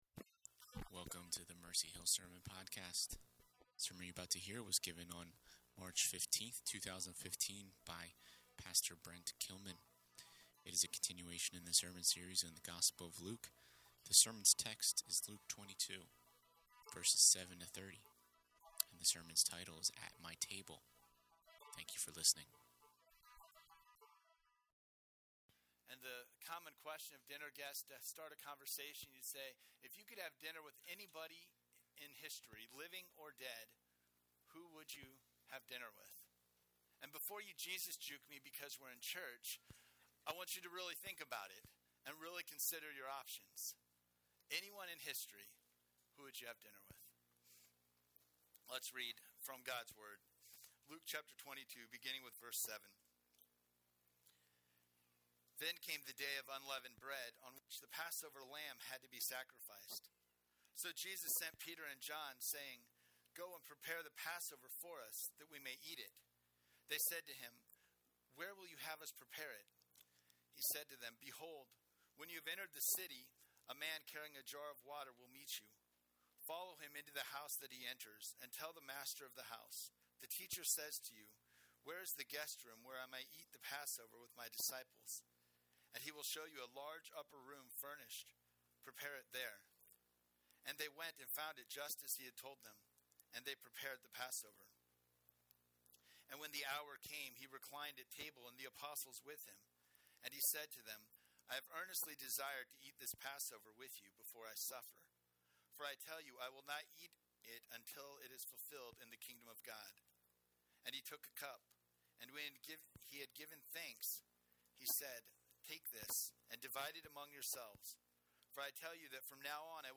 At My Table - Mercy Hill Presbyterian Sermons - Mercy Hill NJ